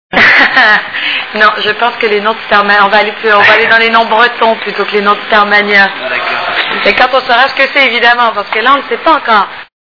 STARMANIA...Interview de Jasmine Roy !!!
( Casino de Paris, Hall d’entrée, 06/02/2000 )